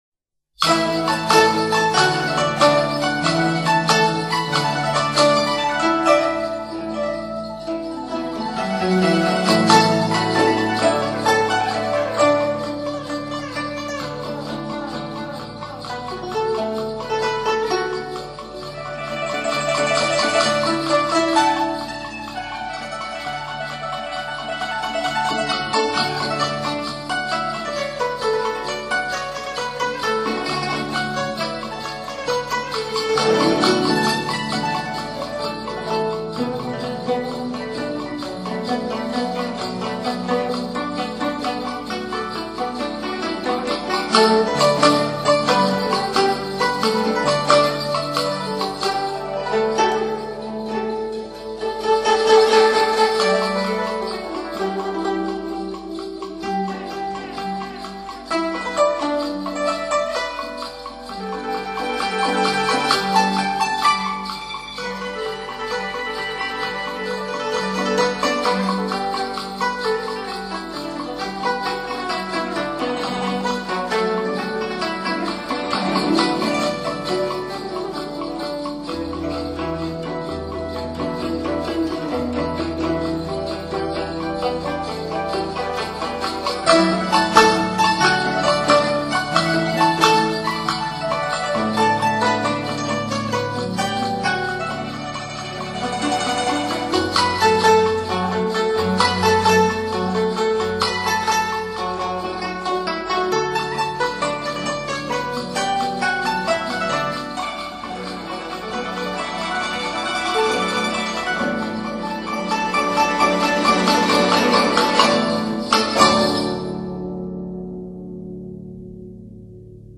乐队运用了多台经过改良的不同高中低音区古筝，在那个年代是一个比较新奇的尝试。